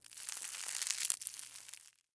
Footsteps